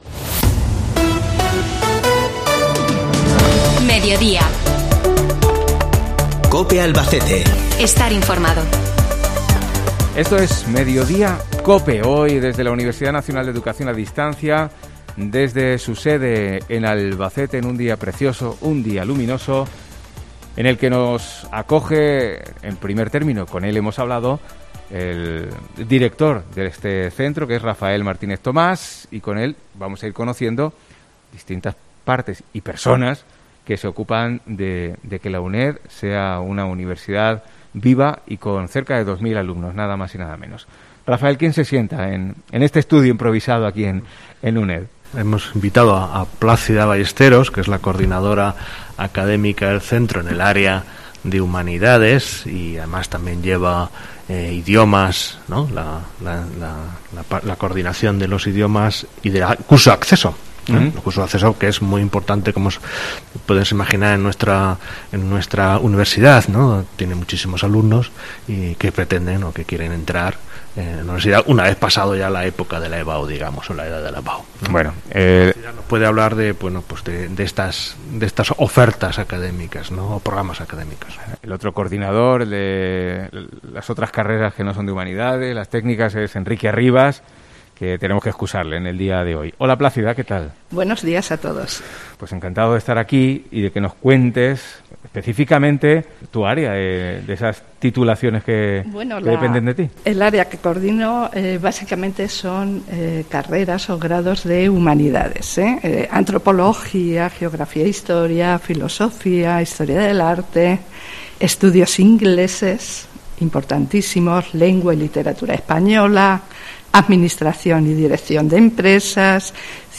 Programa especial este martes desde el centro asociado de la Universidad Nacional de Educación a Distancia de Albacete, creado en 1973 y que desde 1998...
En esta magnífica instalación, COPE Albacete ha realizado este martes un programa especial con el que conocer más de cerca la actividad que comenzara allá por 1973, cuando la oferta universitaria era inexistente, si exceptuamos la posibilidad de estudiar Magisterio.